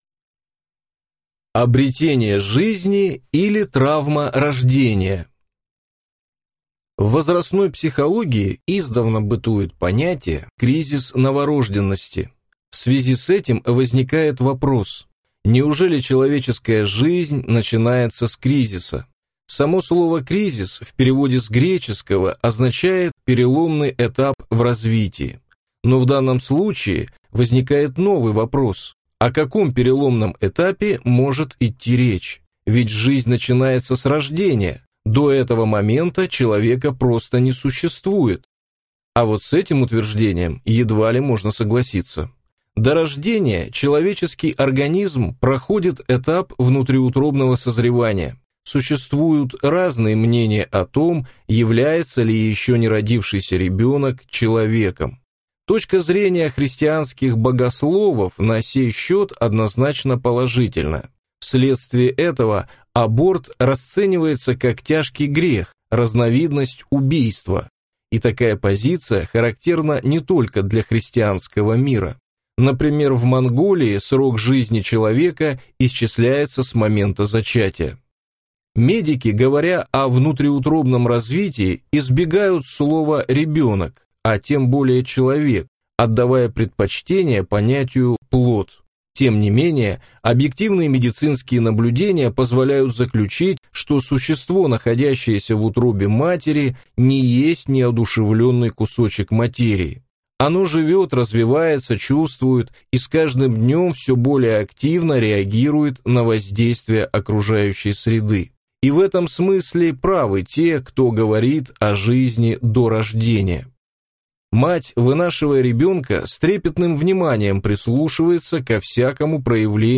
Аудиокнига Детский мир. Советы психолога родителям | Библиотека аудиокниг